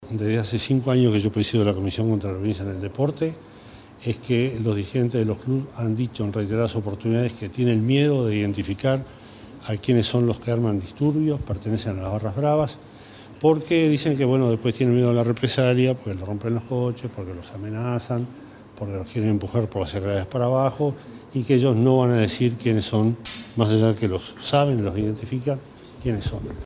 El subsecretario del Ministerio del Interior (MI), Jorge Vázquez, aseguró en conferencia de prensa que los dirigentes de los clubes no han aportado información sobre quienes son los responsables de los disturbios.